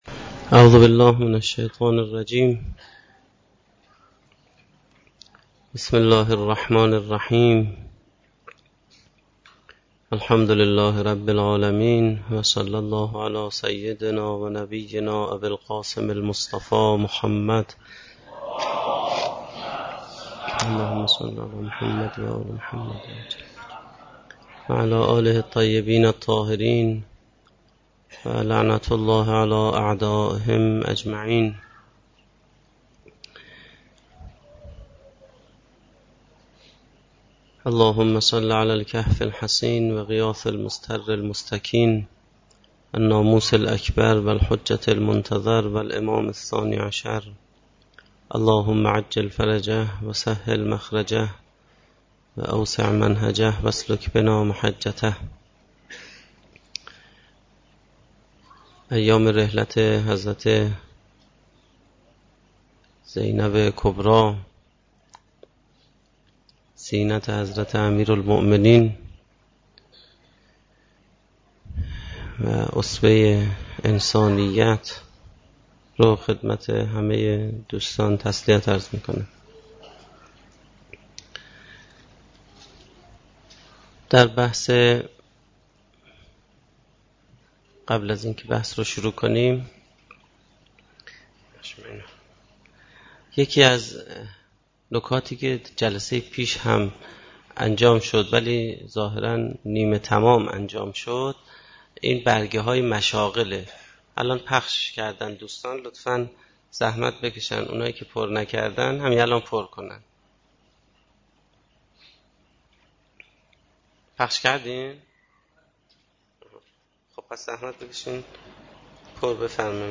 سخنرانی
در جلسه سیر و سلوک قرآنی